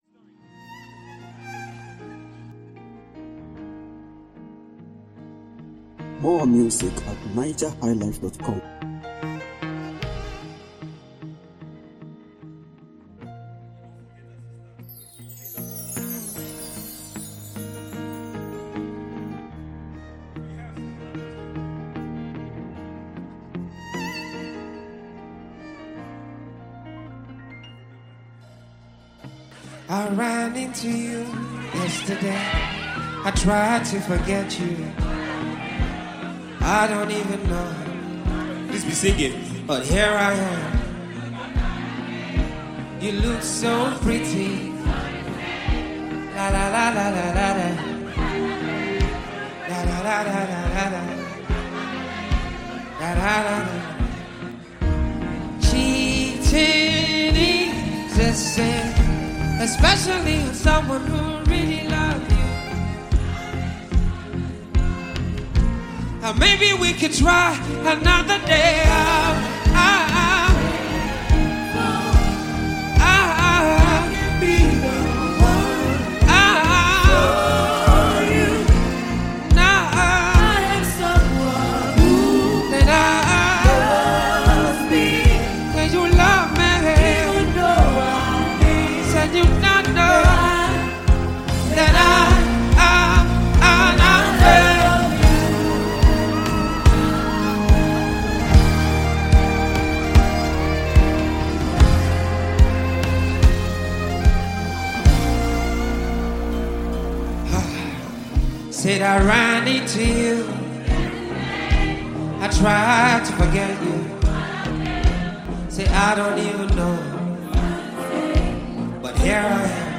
Igbo Gospel Music